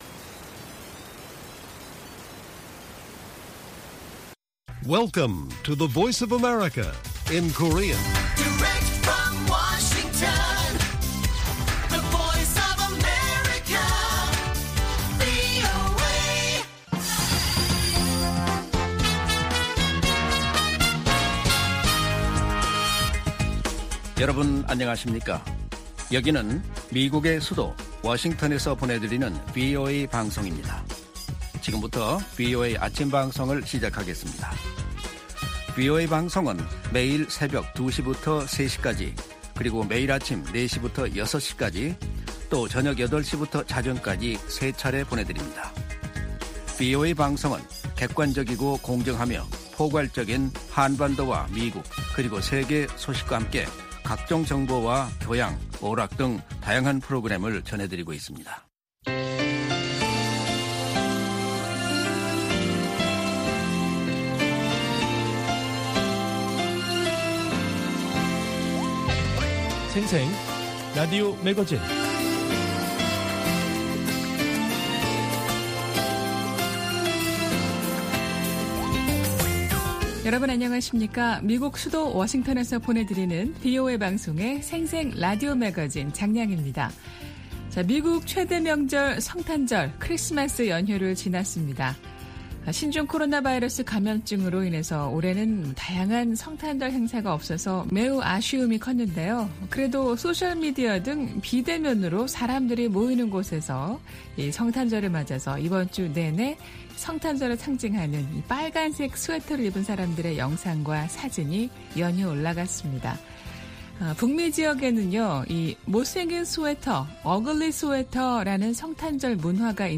VOA 한국어 방송의 일요일 오전 프로그램 1부입니다.